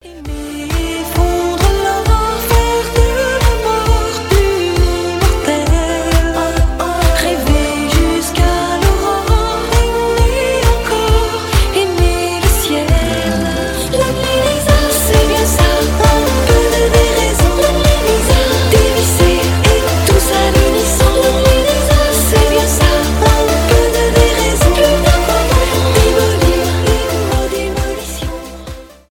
поп
electropop
танцевальные